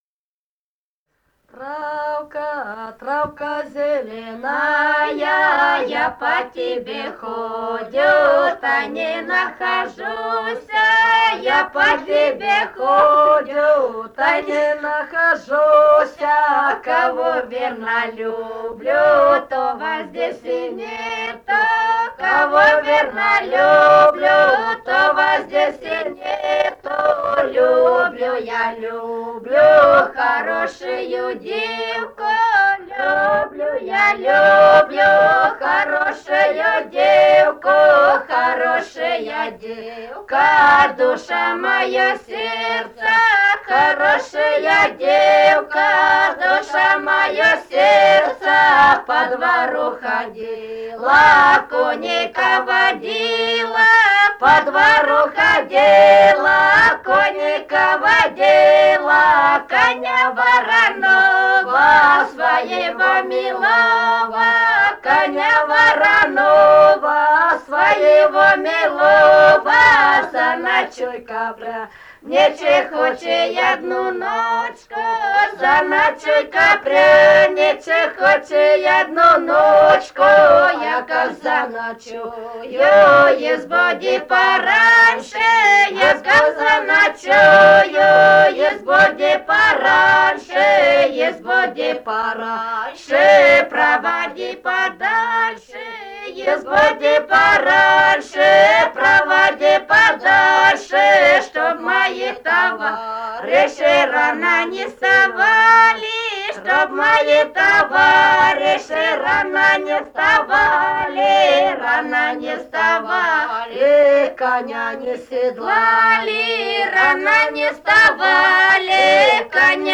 Этномузыкологические исследования и полевые материалы
«Травка, травка зеленая» (плясовая на свадьбе).
Румыния, с. Переправа, 1967 г. И0974-03